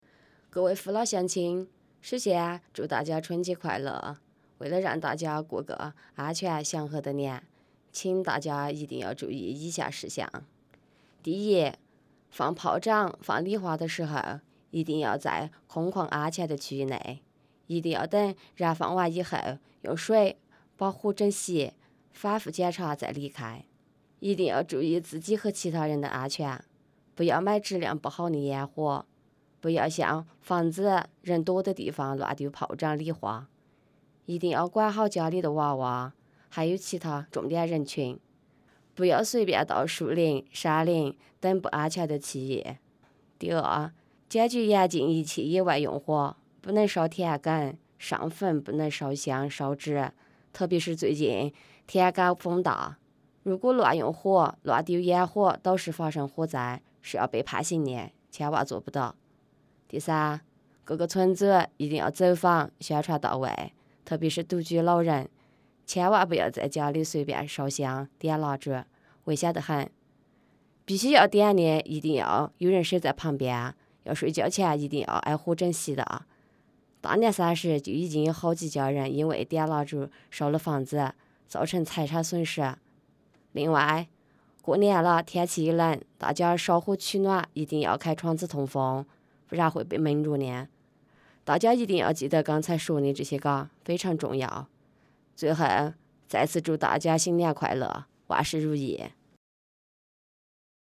春节森林防火（应急广播）